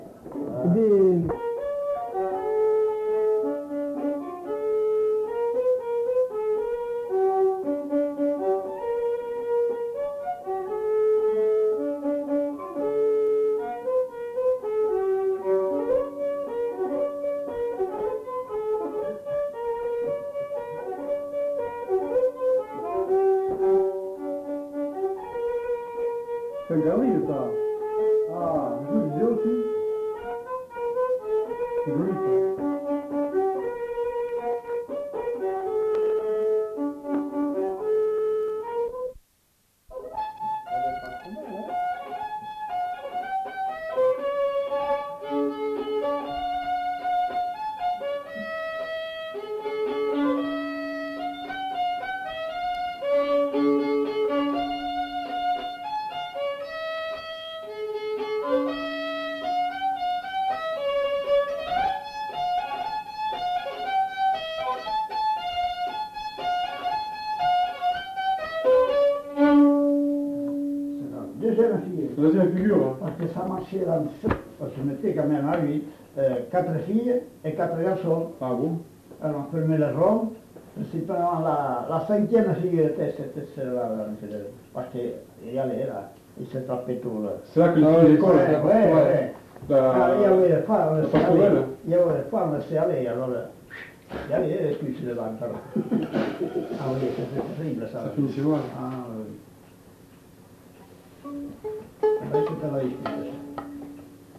Lieu : Saint-Michel-de-Castelnau
Genre : morceau instrumental
Instrument de musique : violon
Danse : quadrille (2e f.)
Notes consultables : Coupure (changement de face).